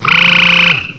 cry_not_palpitoad.aif